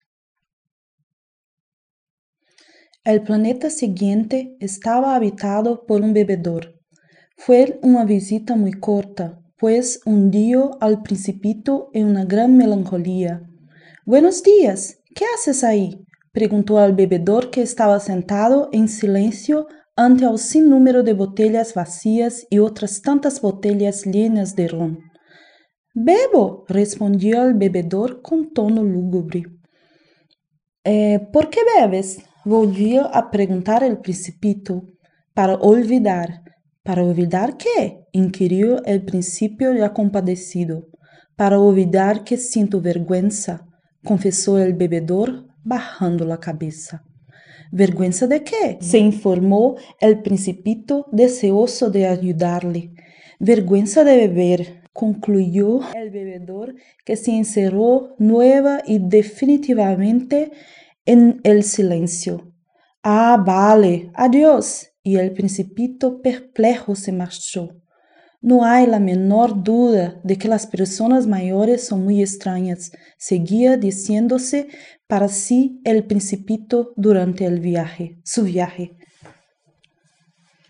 L2 Spanish with L1 Brazilian Portuguese (F, B1):
portuguesebr1.wav